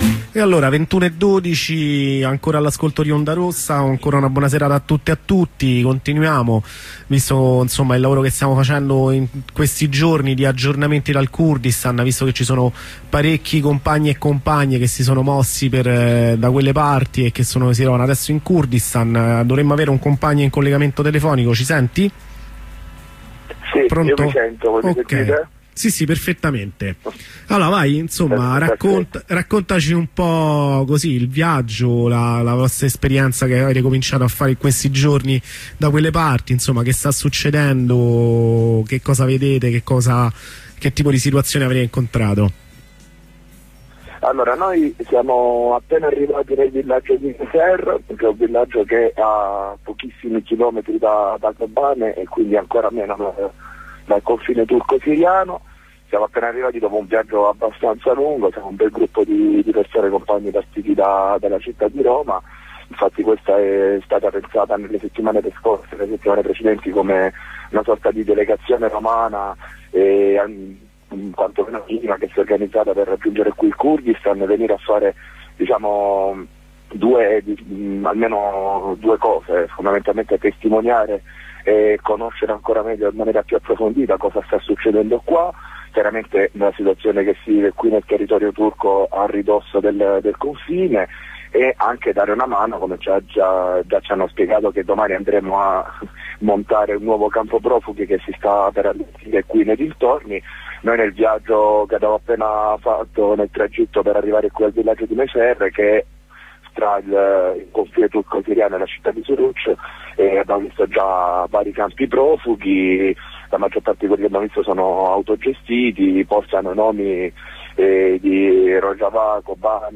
Prima corrispondenza con un convoglio di compagne e compagni arrivati oggi nel Kurdistan turco.